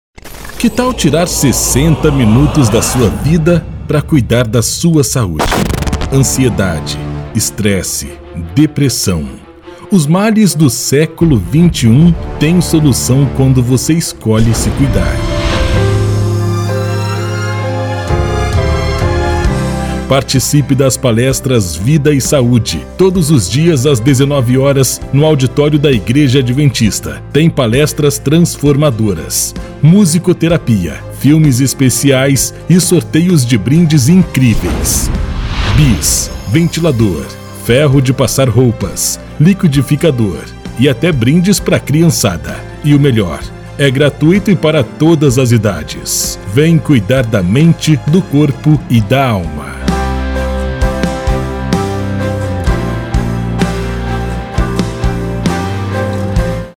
Impacto: